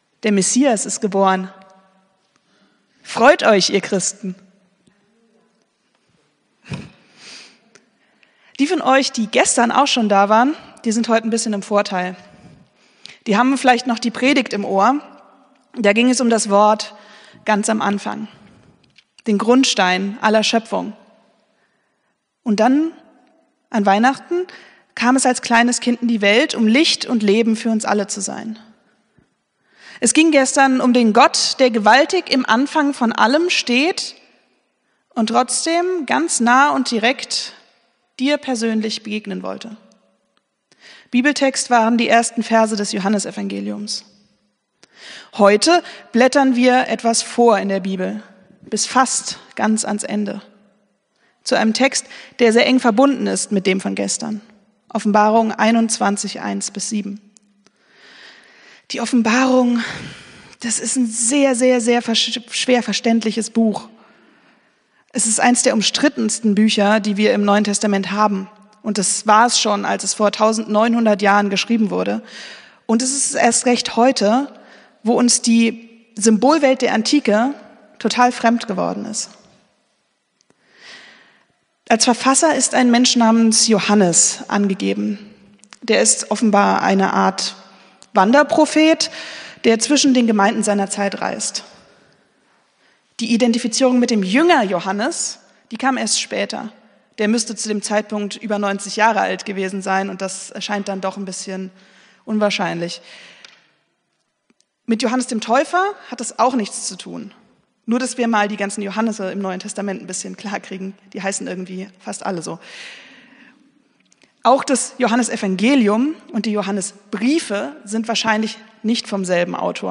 Predigt vom 25.12.2024